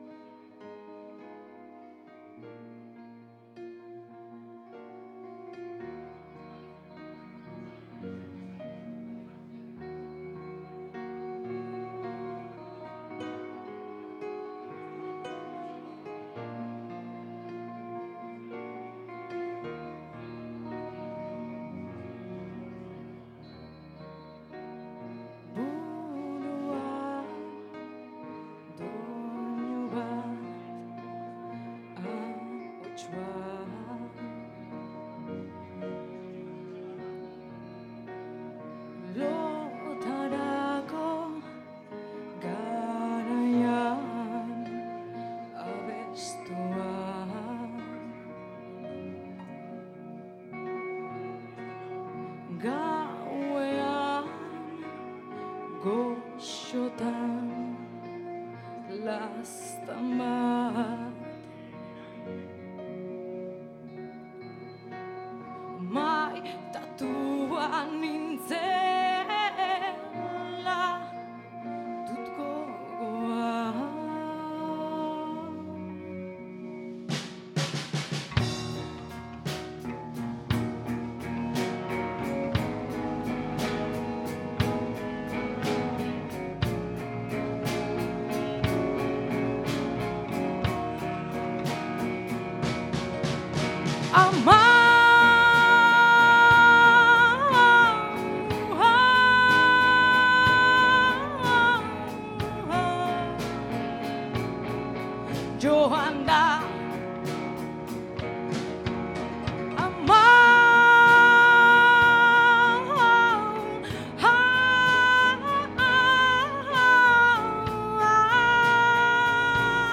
KZko hotsak: Louise Michel, Erbesteko gutunak Hugori Liburu aurkezpena